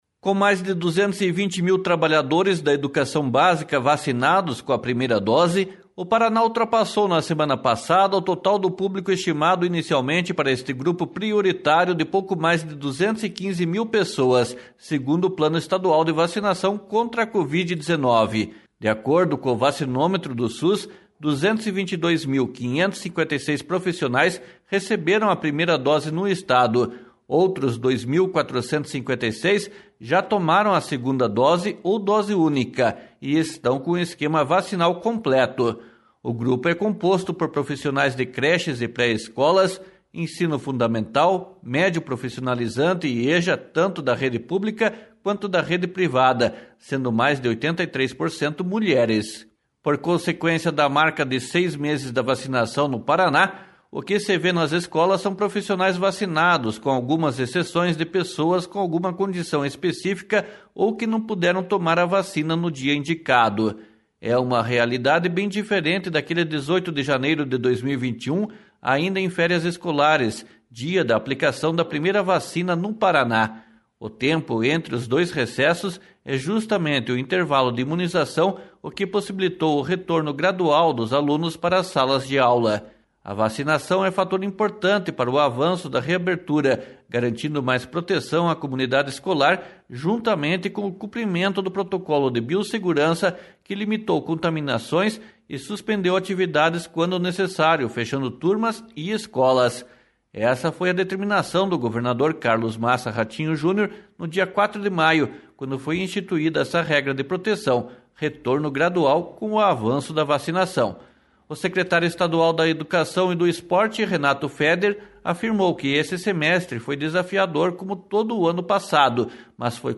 //SONORA RENATO FEDER//